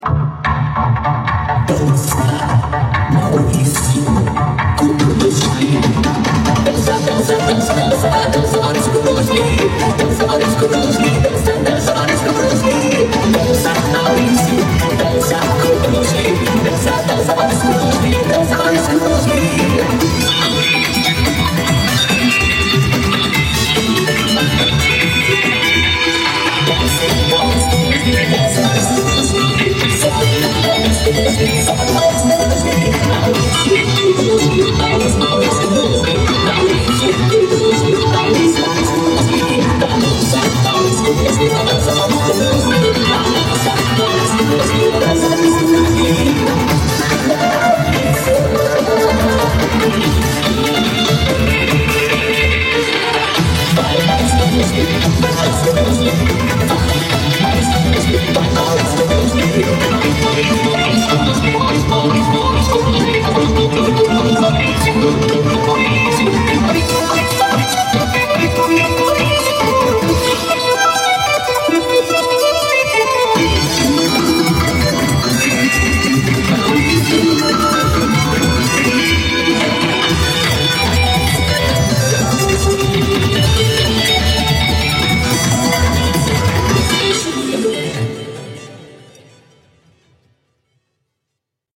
dança mauricio kubrusly vocodificado para